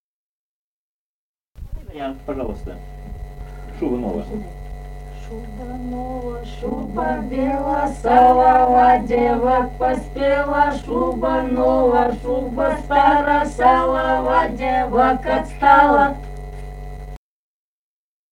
Русские песни Алтайского Беловодья 2 «Шуба нова, шуба бела», игровая на вечеринке у невесты.
Республика Казахстан, Восточно-Казахстанская обл., Катон-Карагайский р-н, с. Фыкалка, июль 1978.